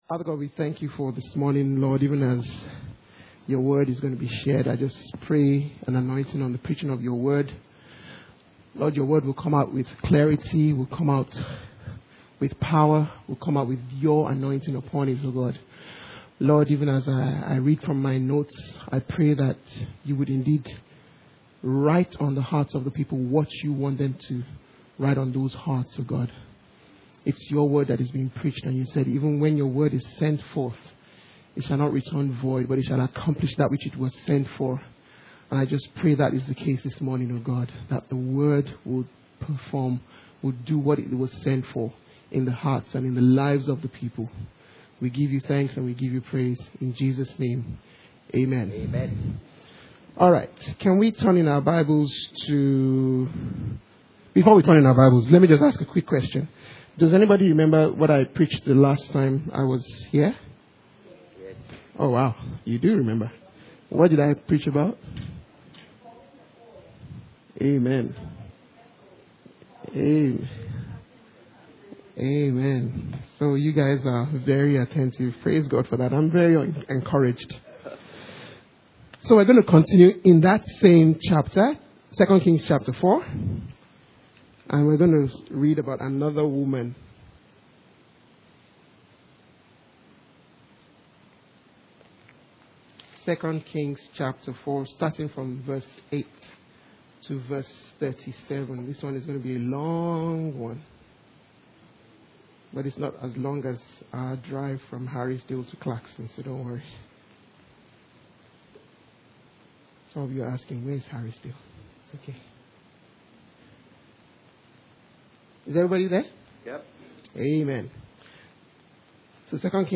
Inhouse Service Type: Sunday Morning « Some Church Issues